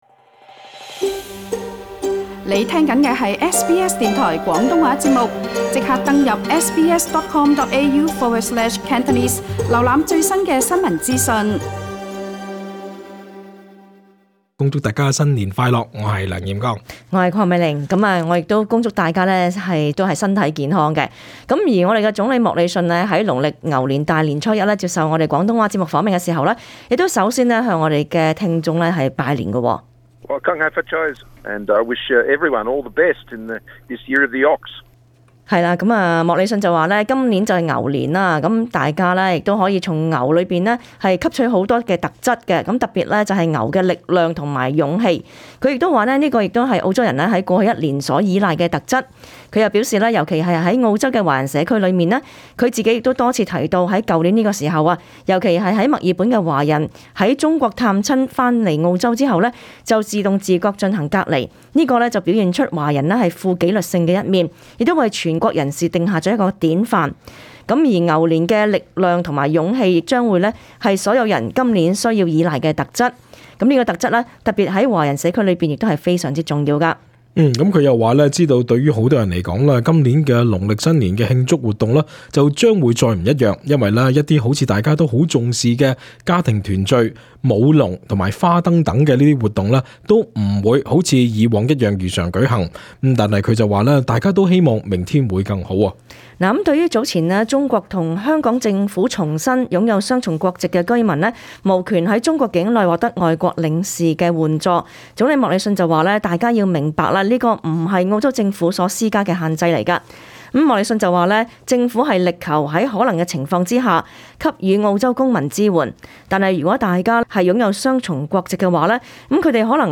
總理莫里遜在農曆牛年大年初一接受廣東話節目訪問時向本台的聽眾拜年。